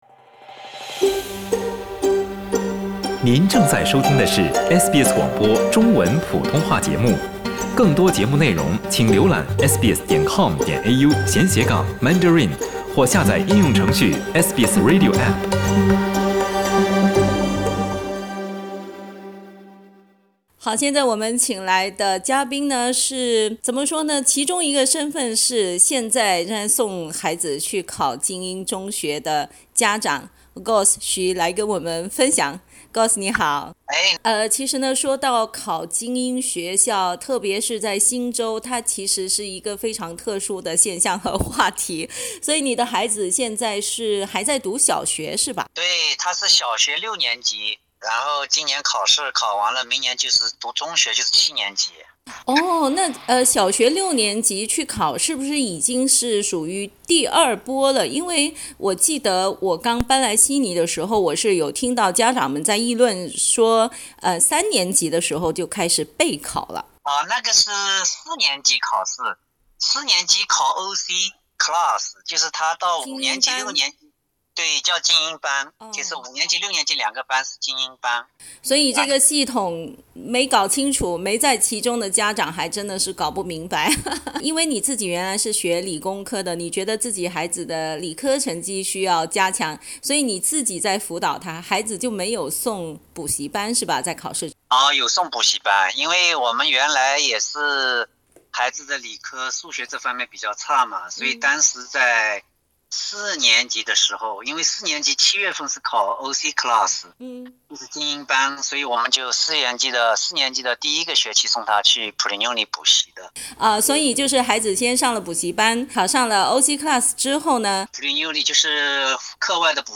（请听报道，本节目为嘉宾观点） 澳大利亚人必须与他人保持至少1.5米的社交距离，请查看您所在州或领地的最新社交限制措施。